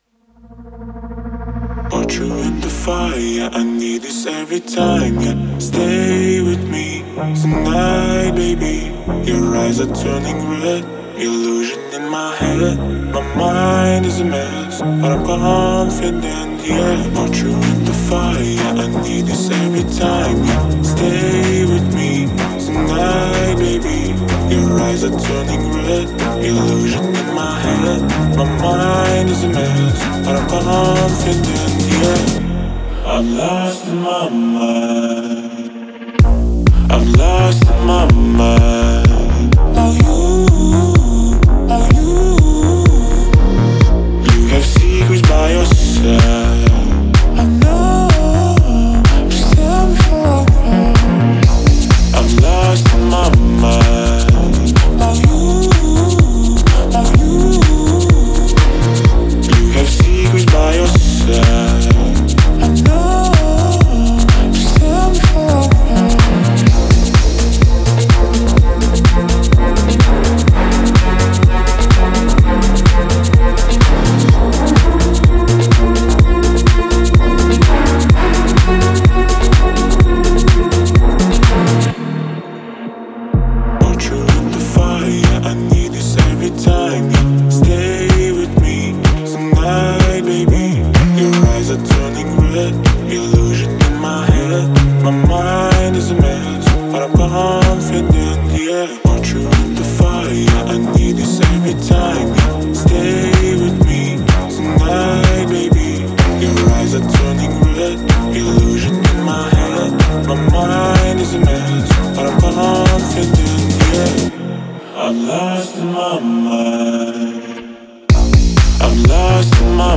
это эмоциональная песня в жанре поп с элементами R&B
Музыка пронизана меланхолией